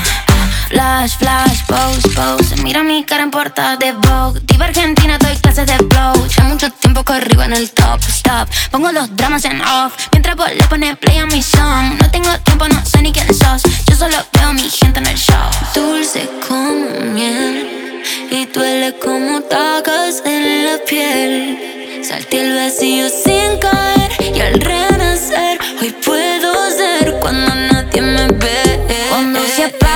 Жанр: Поп / Русские
# Pop in Spanish